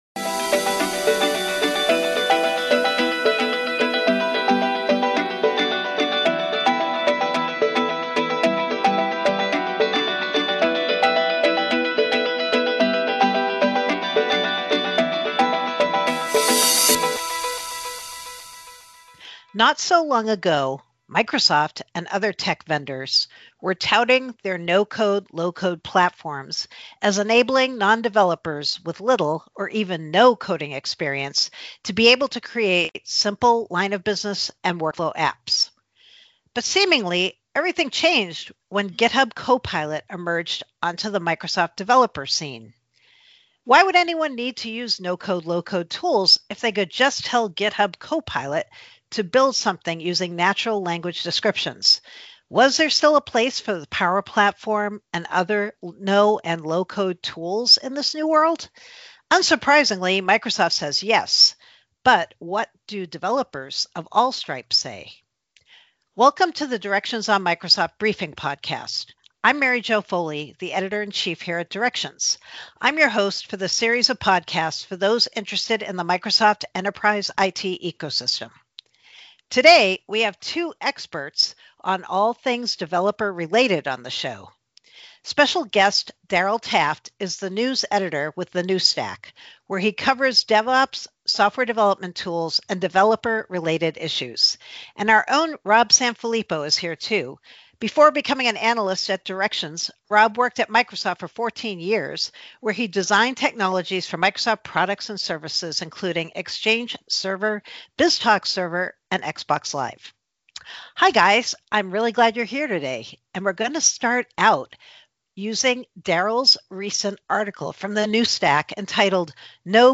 debate the topic.